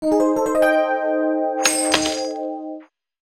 “3秒チャリン音”。